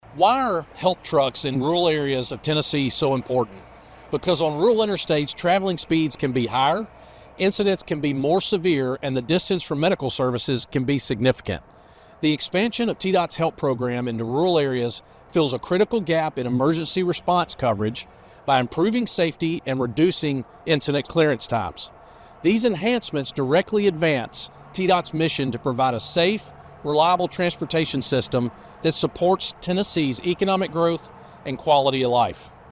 T-DOT Commissioner Will Reid tells us more about the project.